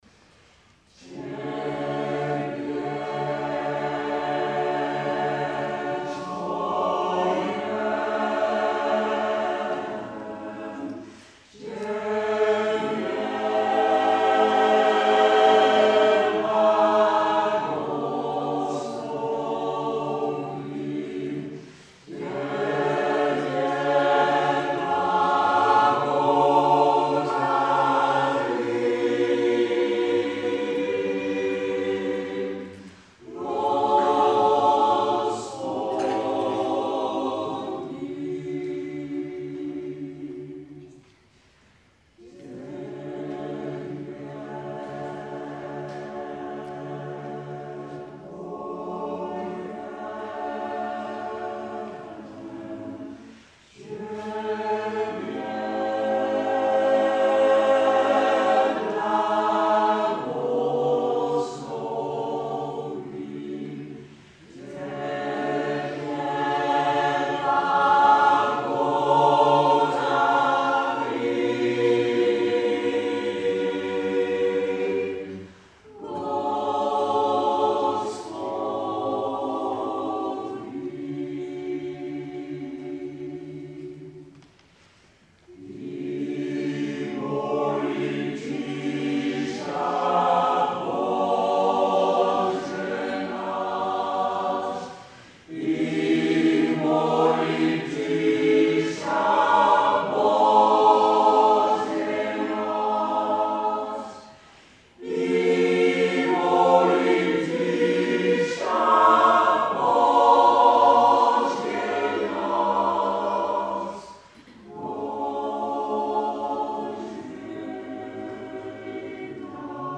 Byzantijns Koor